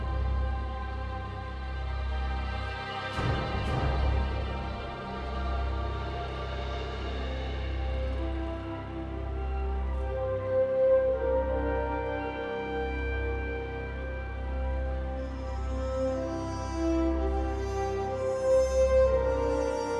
Musique multipistes.